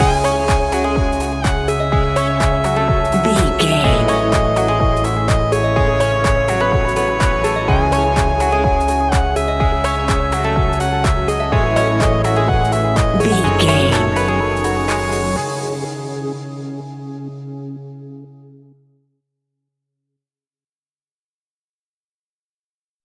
Aeolian/Minor
C#
groovy
hypnotic
dreamy
synthesiser
drum machine
electric guitar
funky house
deep house
nu disco
upbeat
funky guitar
clavinet
synth bass